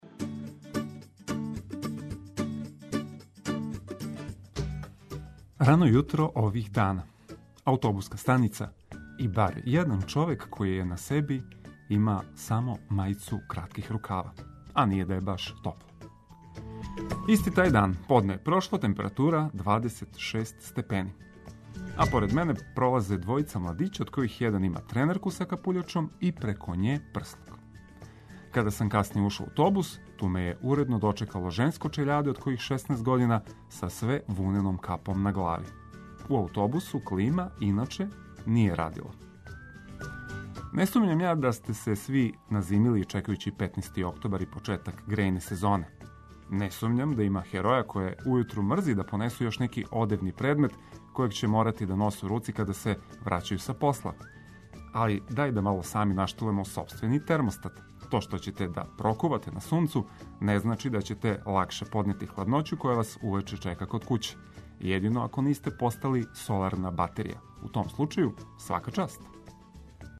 Пратићемо стање на путевима и где има измена у саобраћају, када метеоролози најављују промену времена, као и где су најављена искључења струје и воде. Ослушните нас, уживајући уз савршене хитове.